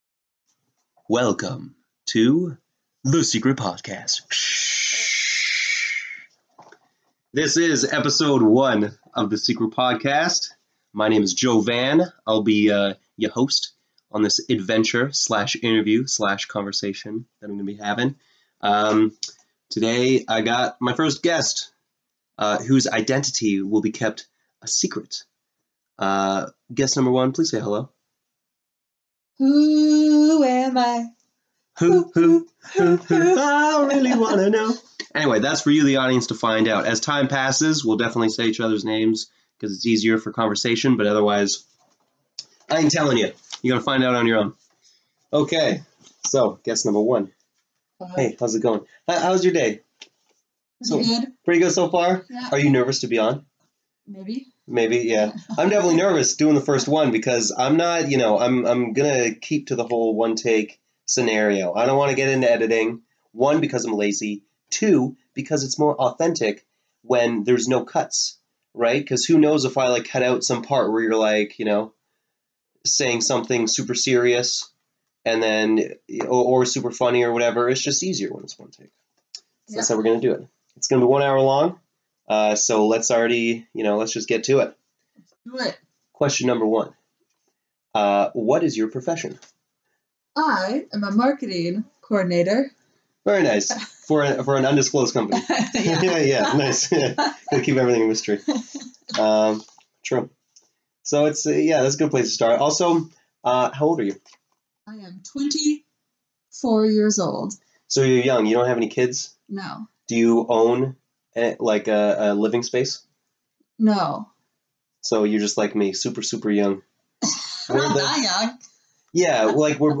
I interview someone worth interviewing first.